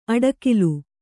♪ aḍakilu